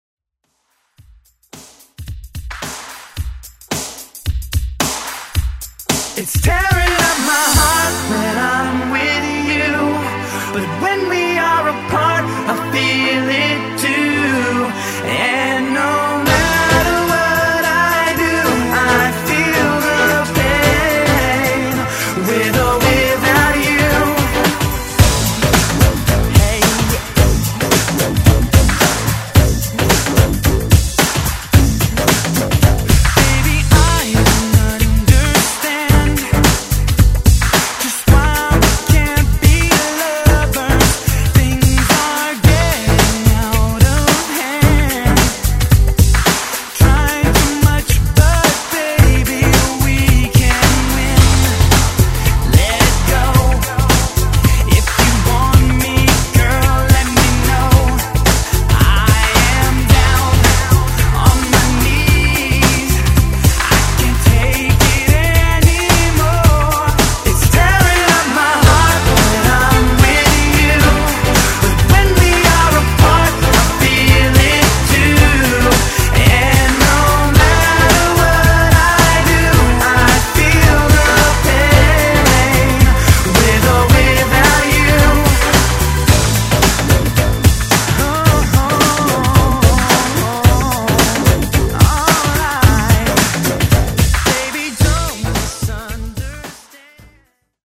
Genre: 90's Version: Clean BPM: 110 Time